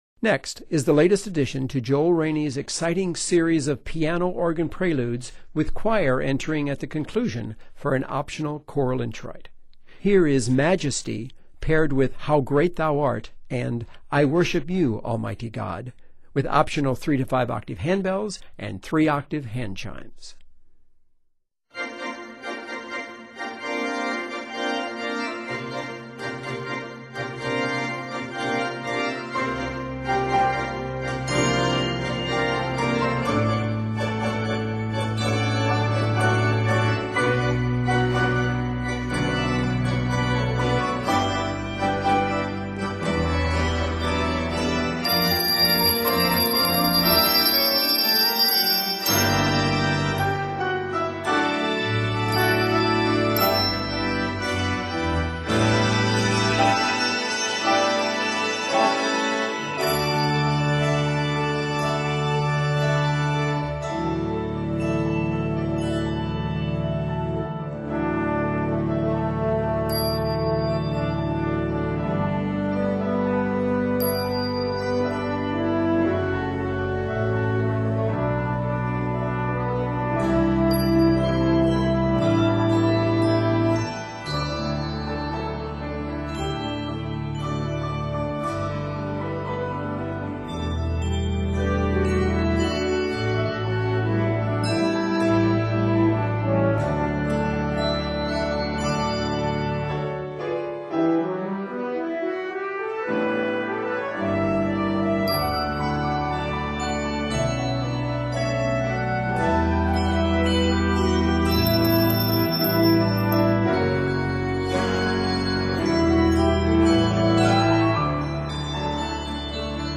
This festive worship opener
piano/organ duets